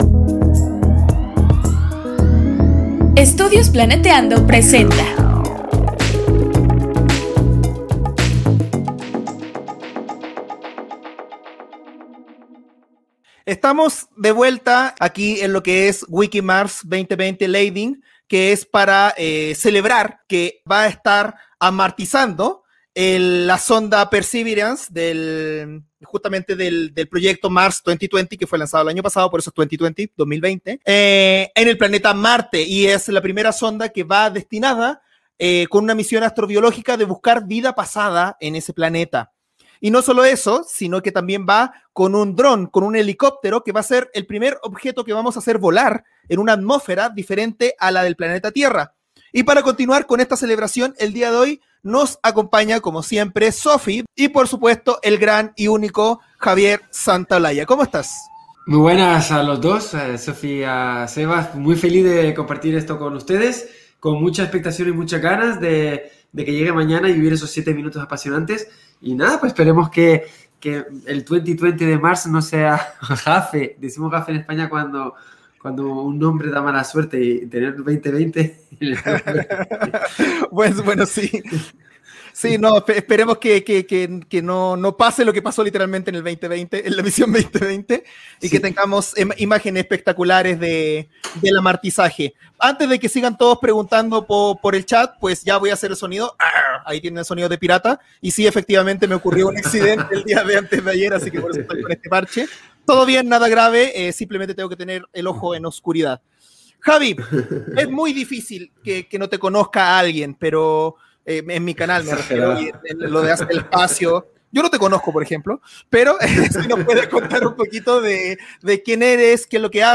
Plática con Javier Santaolalla de Date un Vlog